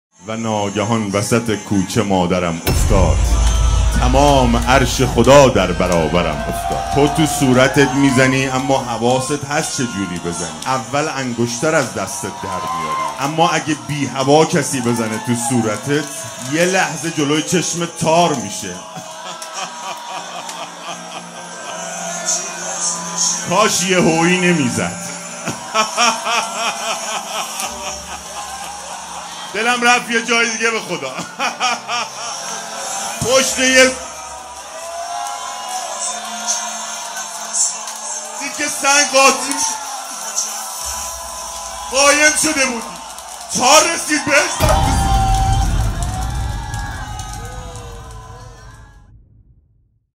صابر خراسانی | هیئت جنت العباس کاشان | ایام فاطمیه 1441